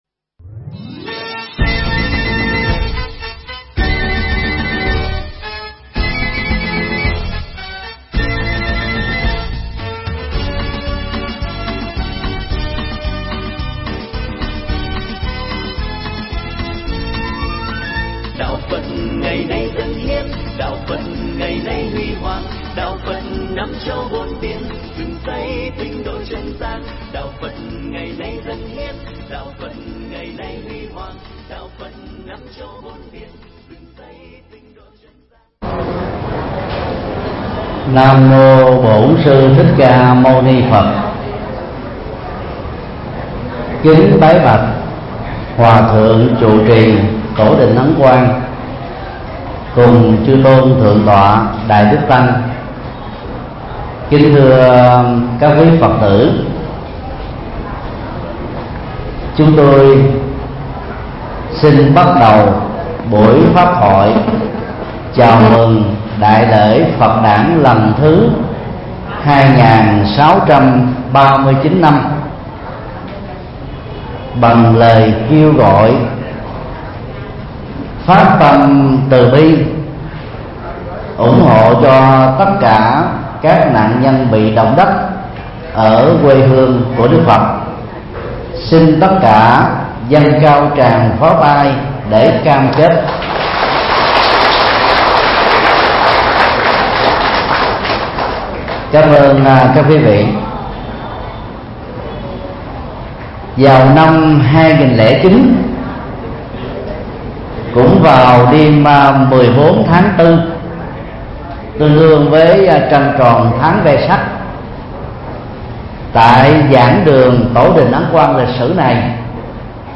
Mp3 Thuyết Pháp Bảy lý do tiếp tục theo đạo Phật – Thầy Thích Nhật Từ Giảng tại chùa Ấn Quang 243 Sư Vạn Hạnh, Phường 9, Quận 10, ngày 31 tháng 5 năm 2015